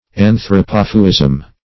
Search Result for " anthropophuism" : The Collaborative International Dictionary of English v.0.48: Anthropophuism \An`thro*poph"u*ism\ ([a^]n`thr[-o]*p[o^]f"[-u]*[i^]z'm), n. [Gr.
anthropophuism.mp3